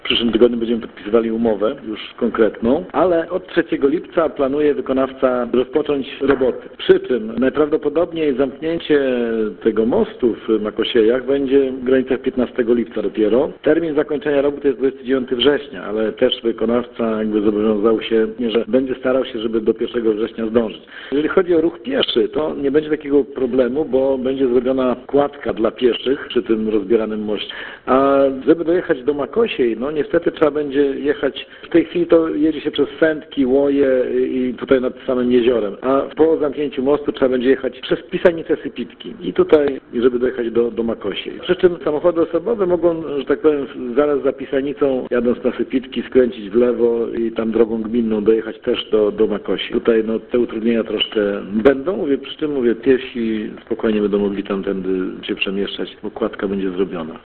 Natomiast dla pojazdów wyznaczony zostanie objazd, mówi starosta powiatu ełckiego Marek Chojnowski.